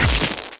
Machine ambient sounds
spark.ogg